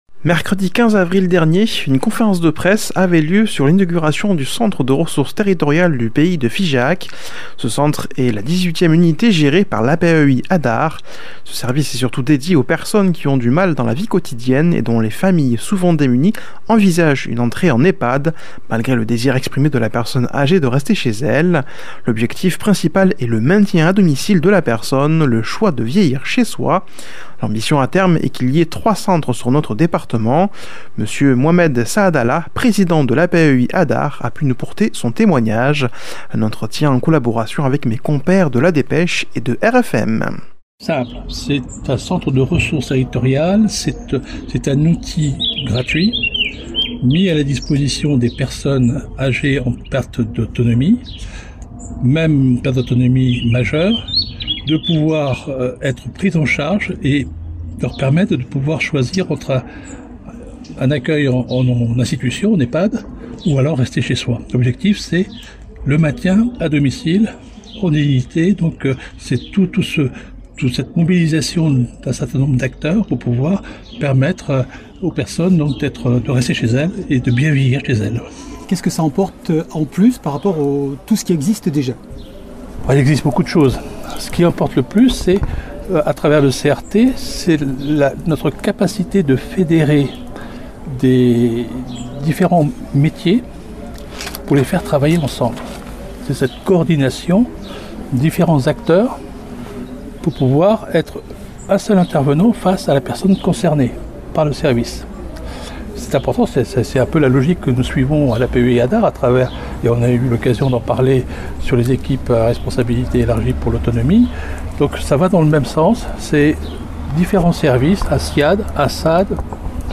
Conférence inauguration CRT
Mercredi 15 Avril dernier, une conférence de presse avait lieu sur l'inauguration du Centre de Ressources Territorial du pays de Figeac.
Un itw en collaboration avec la Dépêche et RFM